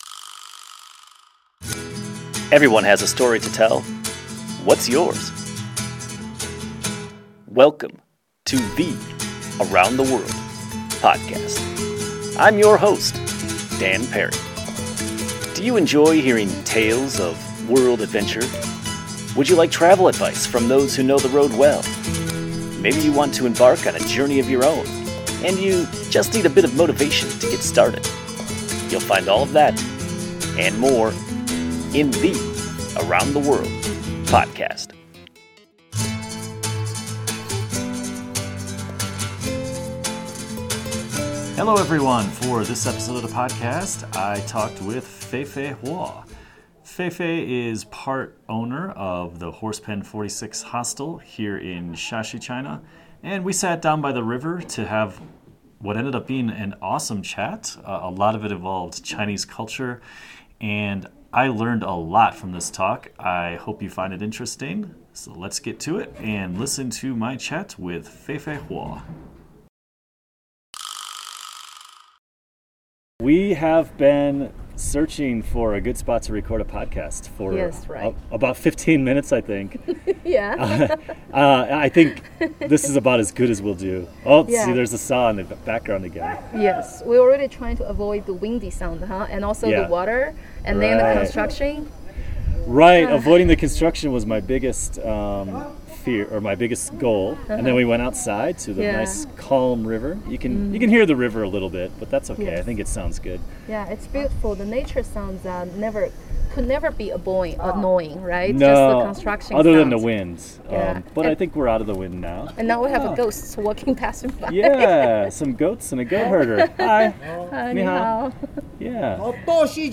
We recorded this podcast while sitting by the river in Shaxi, China at the end of yet another picture-perfect day. Our discussion was mostly about cultural differences between East and West, and she taught me a lot.